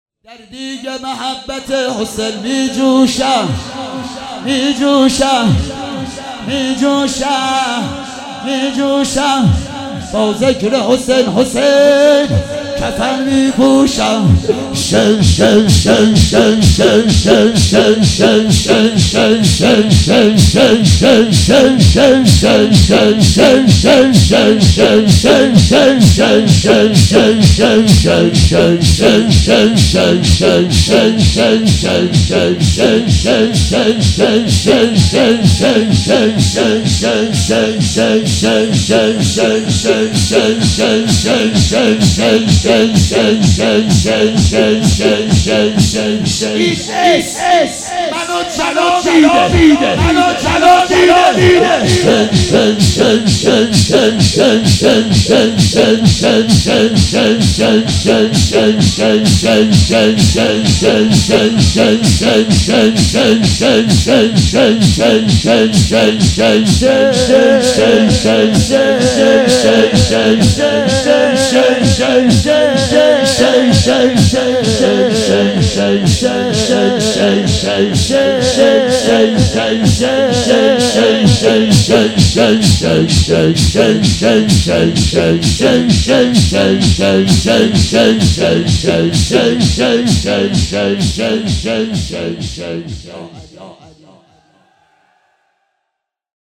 شب تاسوعا محرم 96 - ذکر حسین